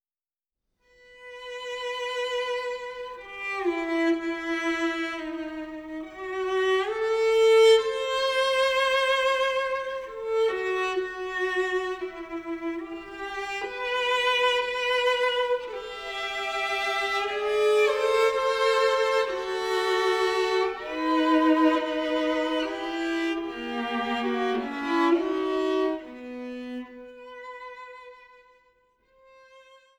im Studio der Musikhochschule aufgenommen